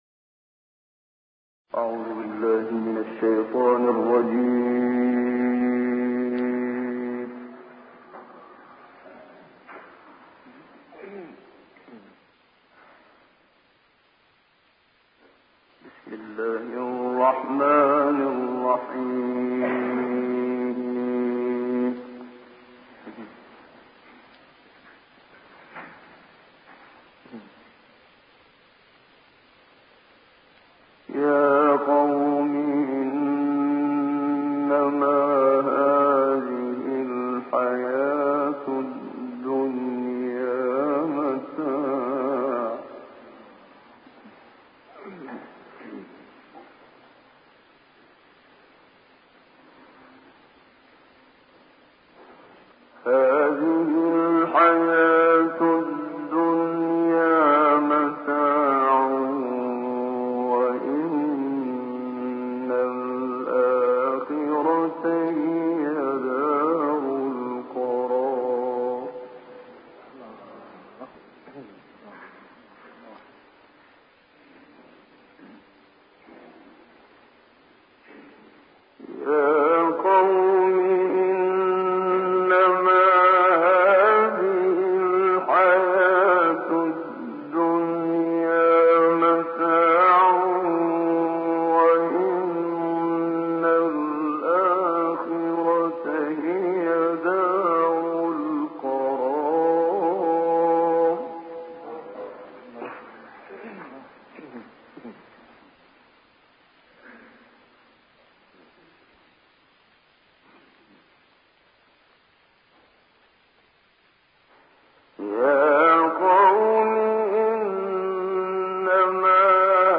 استاد منشاوی در لیبی